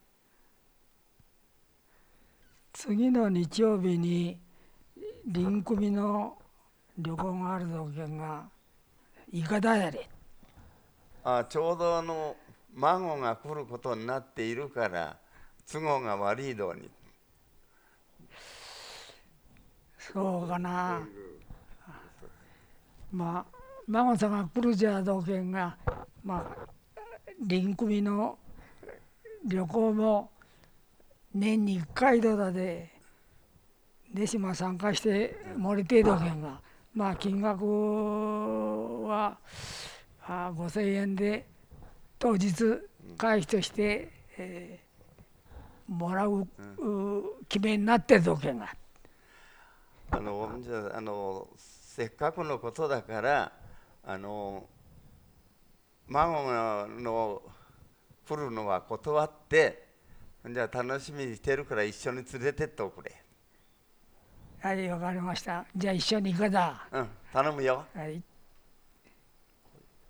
会話（ロールプレイ） ─雨畑─
[4] でし：共通語の「ぜ」/ze/ に対応して[de], 「ひ」/hi/ に対応して [ɕi] と発音されている。
[5] まこ゜か゜ー　のー：[maŋoŋaːnoː] 「か゜」の後にフィラーの「あのー」が続いた発話だと思われる。